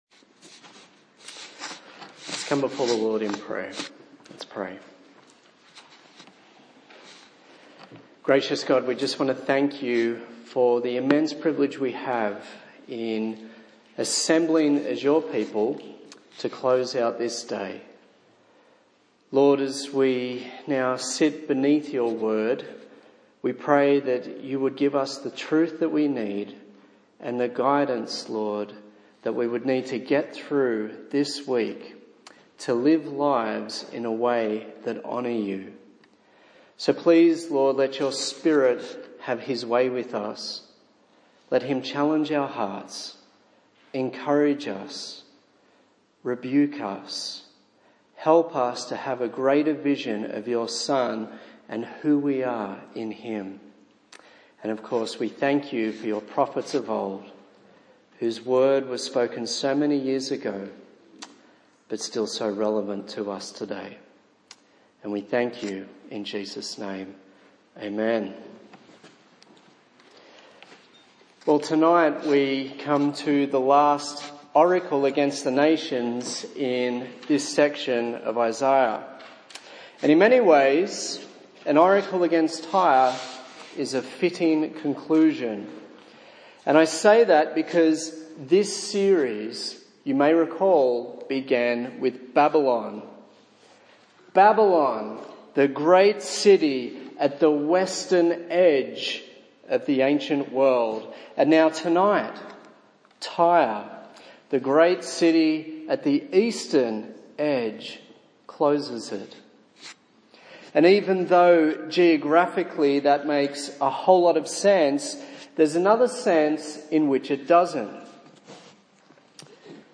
A sermon in the series on the book of Isaiah. The merchants are silent; to bring down her pride; so that you will be remembered.